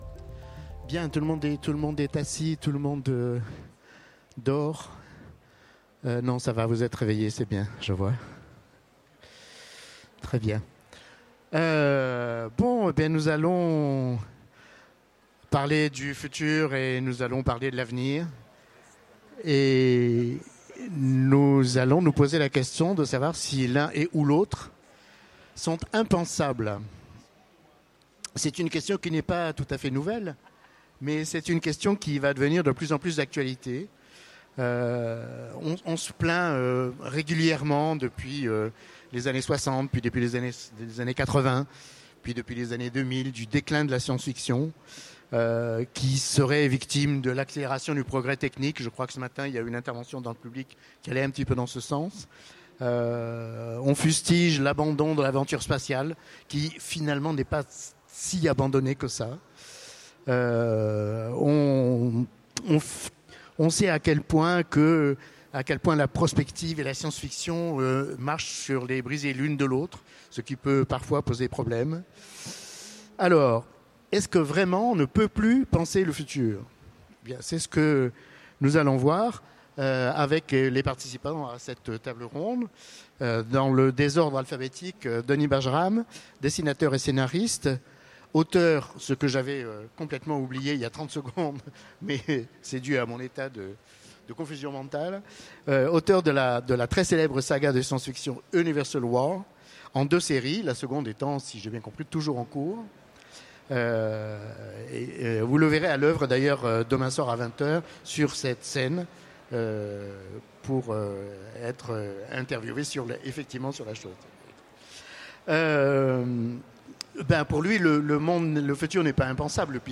Utopiales 2015 : Conférence De l’avenir faisons table rase
Conférence